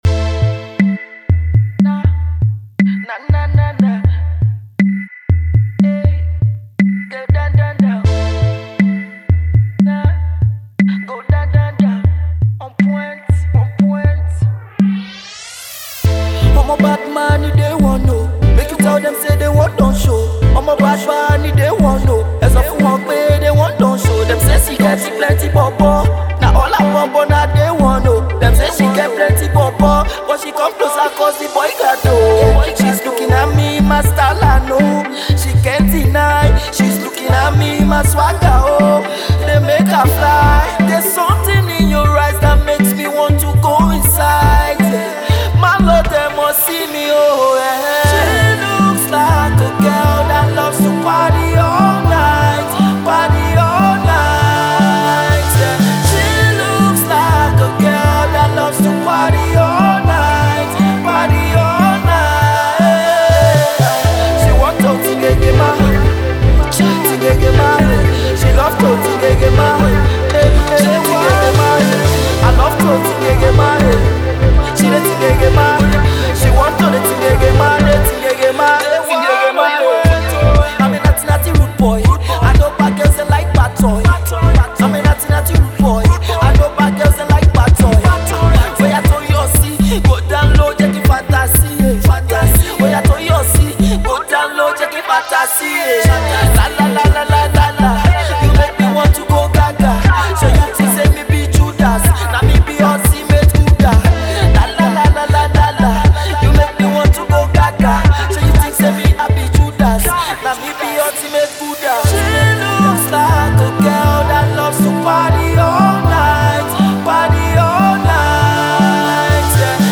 is a rising Nigerian Afro-Pop artist based in Cyprus
beat heavy singles
made to make your body rock